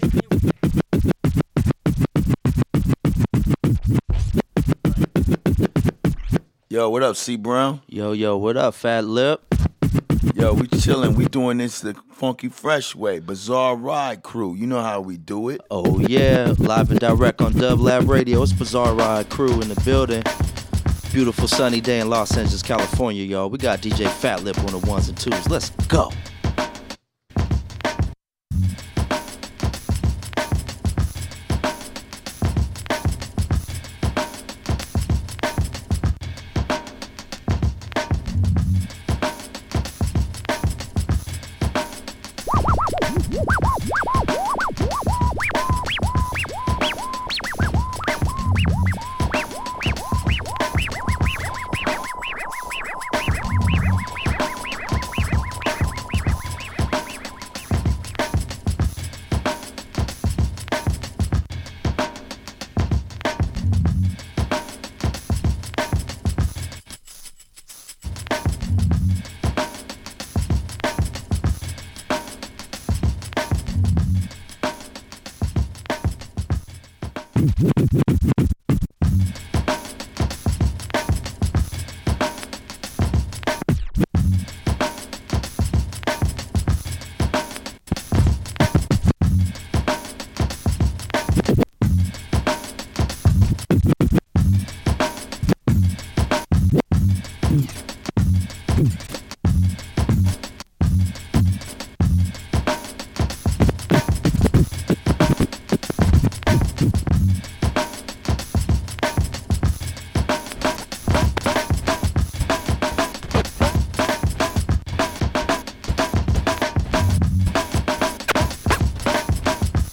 Beats Funk/Soul Hip Hop